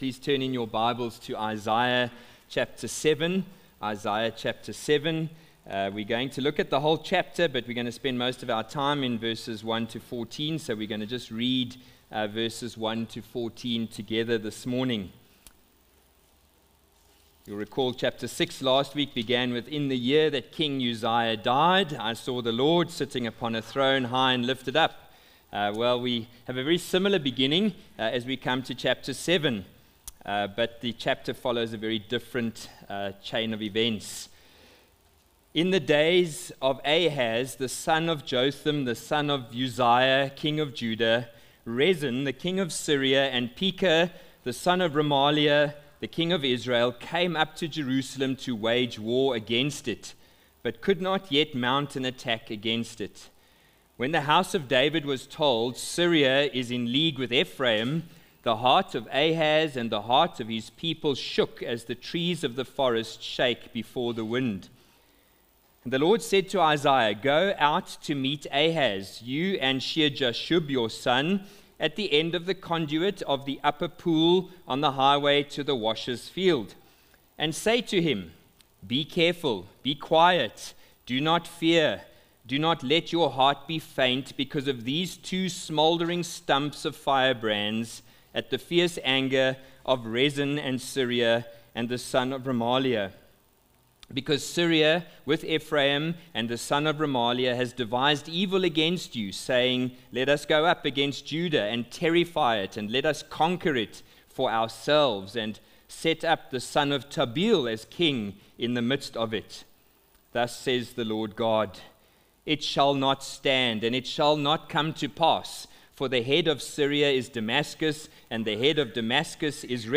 Sermons | Honeyridge Baptist Church